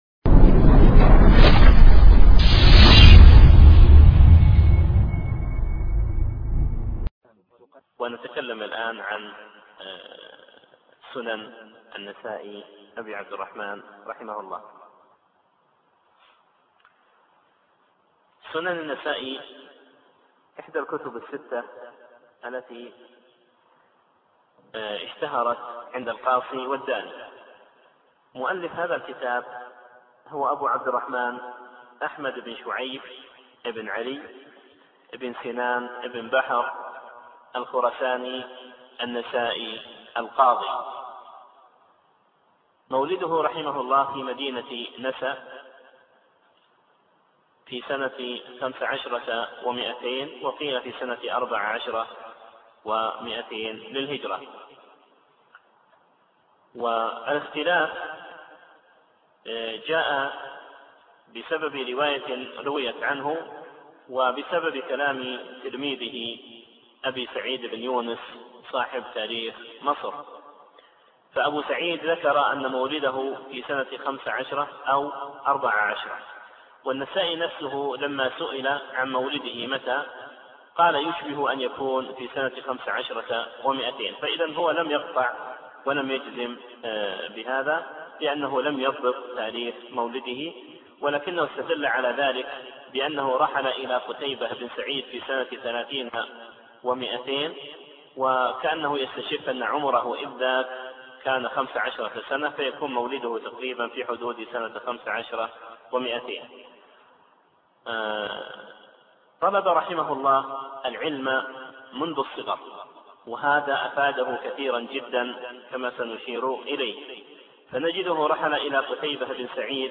من مناهج المحدثين... (سنن النسائي)- الدرس الثامن والاخير